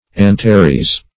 Search Result for " antares" : Wordnet 3.0 NOUN (1) 1. the brightest star in Scorpius ; The Collaborative International Dictionary of English v.0.48: Antares \An*ta"res\, n. [Gr.